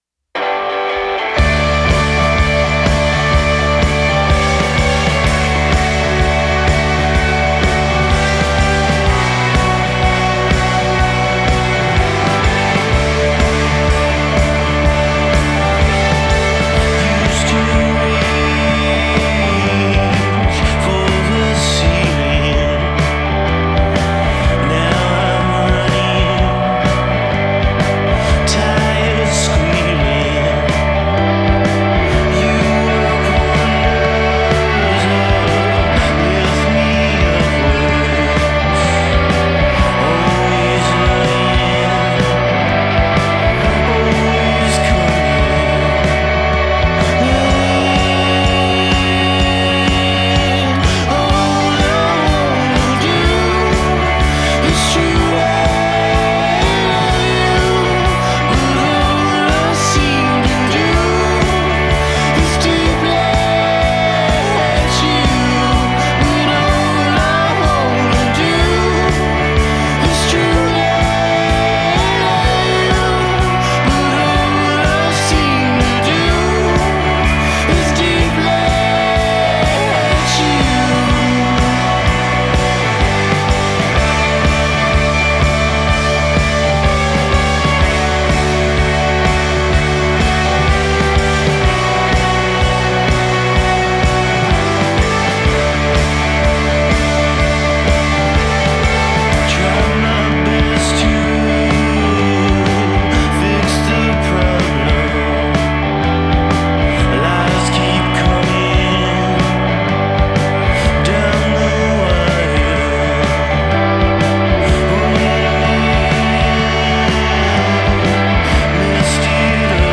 droney guitars, indelible chorus